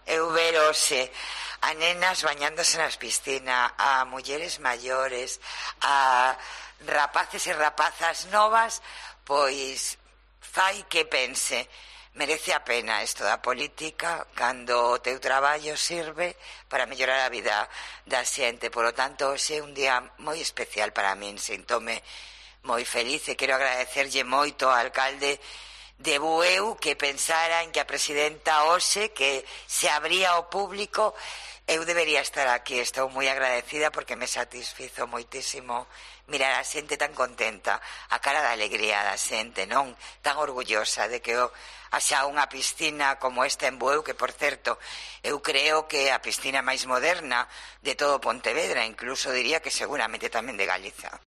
Declaraciones de Carmela Silva en Herrera en Cope + Pontevedra y Cope Ría de Arosa